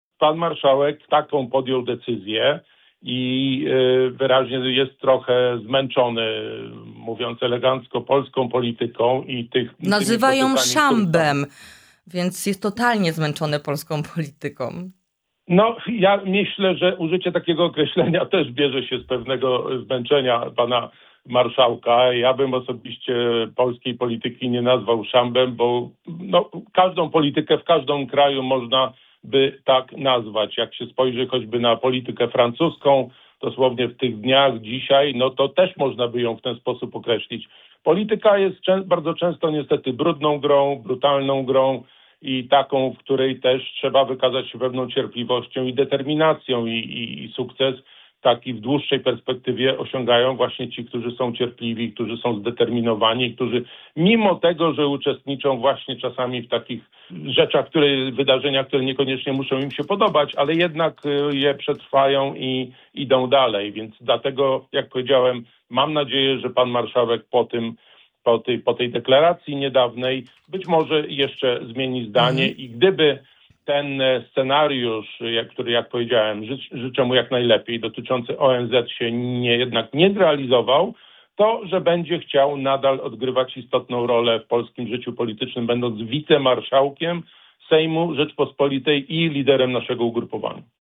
O sytuacji w partii, potencjalnych odejściach, ewentualnej tece wiceministra, a także głosowaniu nad odwołaniem przewodniczącej Komisji Europejskiej rozmawiamy z europosłem Krzysztofem Kobosko.